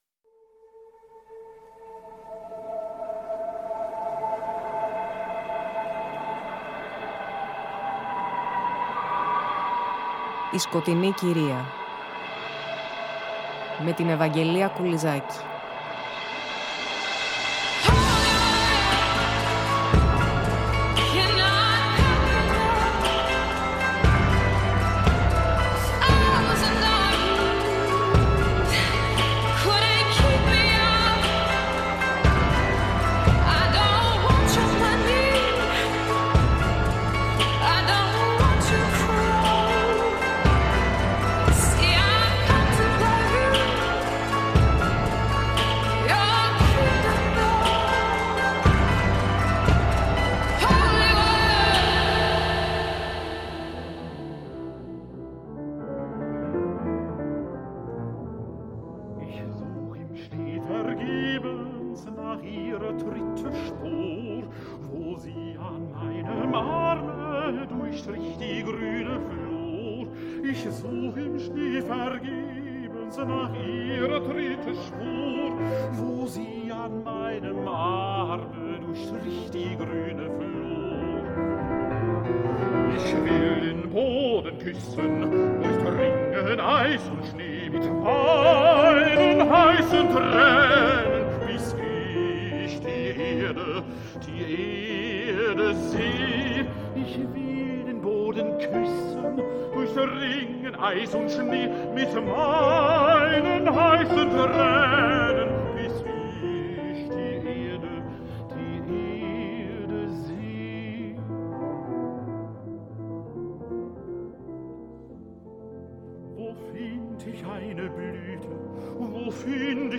Πλαισιώνουμε μουσικά με κομμάτια από το ‘ ‘Winterreise” , τον κύκλο τραγουδιών του Franz Schubert σε ποίηση Wilhelm Müller .